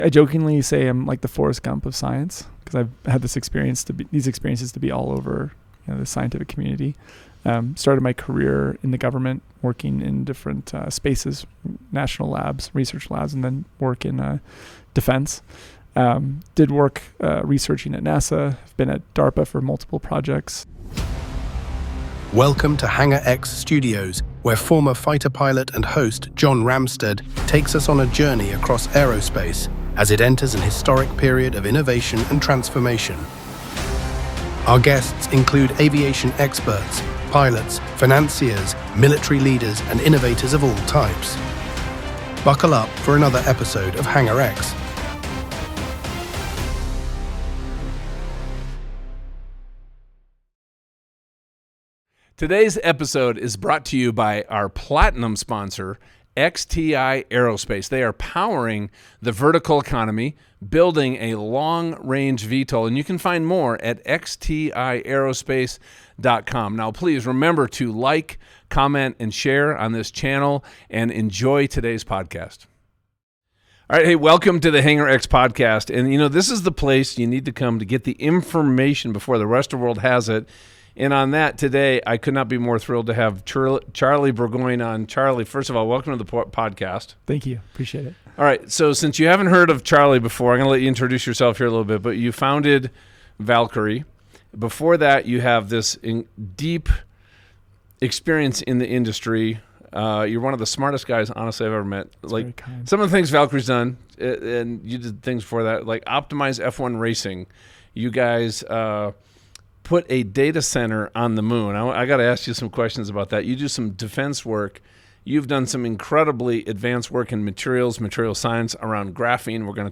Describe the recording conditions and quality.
The conversation weaves deep scientific insights with real-world applications, all rooted in values-driven innovation.